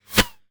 bullet_flyby_fast_05.wav